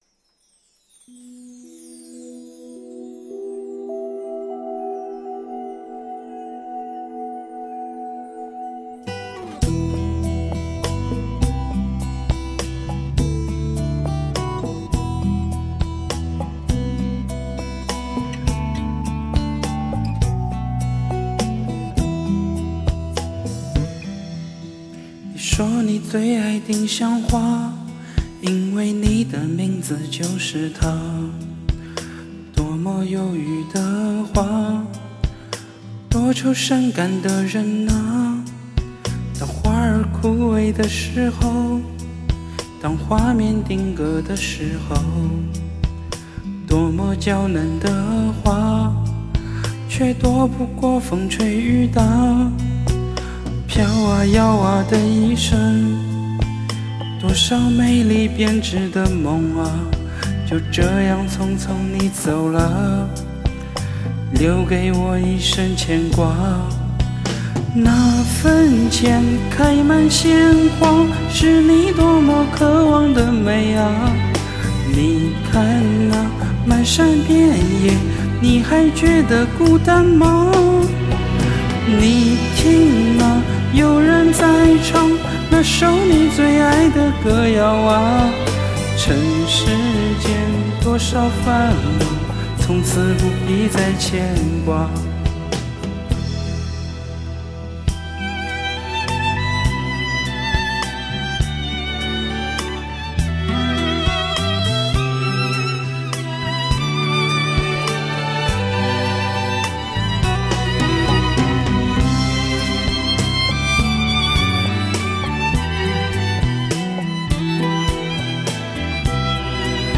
我这走调、破音的歌也被你拿出来水个帖